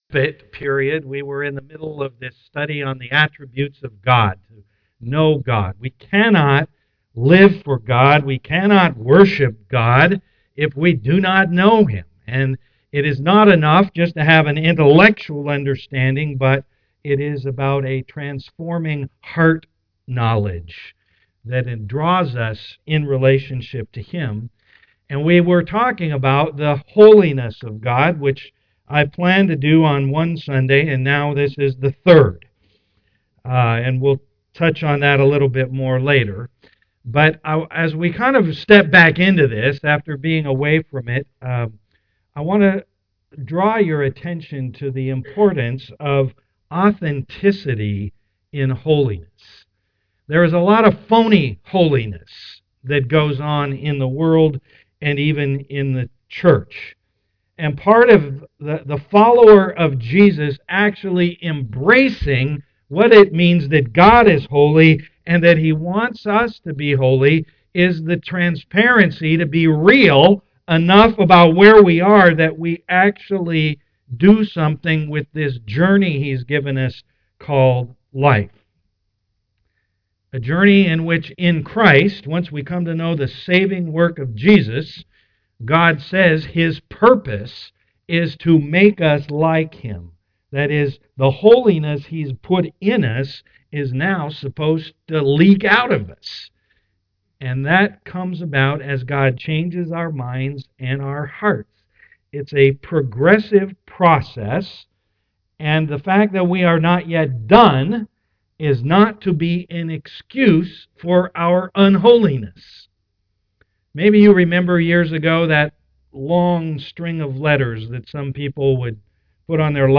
Know God Service Type: am worship Download Files Notes Bulletin Topics